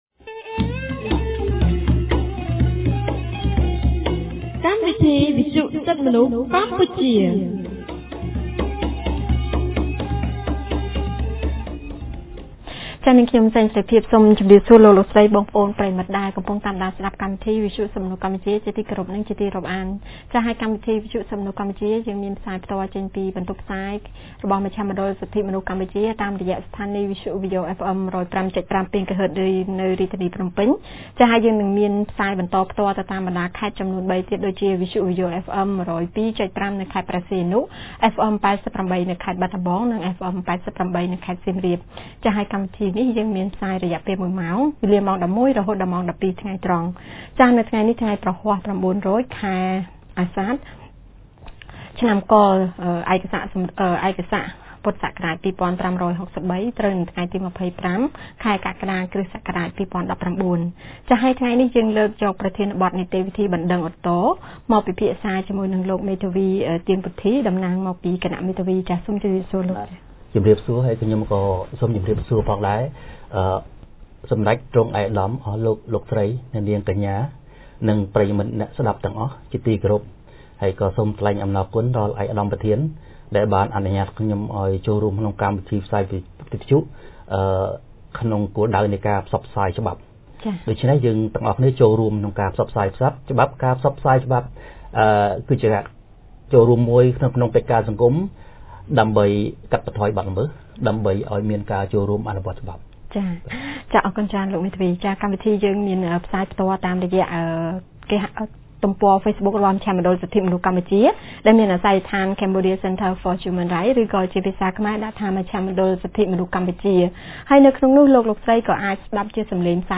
ថ្ងៃព្រហស្បតិ៍ ទី២៥ ខែកក្កដា ឆ្នាំ២០១៩ គម្រាងសិទ្ធិទទួលបានការជំនុំជម្រះដោយយុត្តិធម៌ នៃមជ្ឈមណ្ឌលសិទ្ធិមនុស្សកម្ពុជា បានរៀបចំកម្មវិធីវិទ្យុក្រោមប្រធានបទស្តីពី នីតិវិធីបណ្ដឹងឧទ្ធរណ៍។